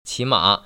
[qĭmă] 치마  ▶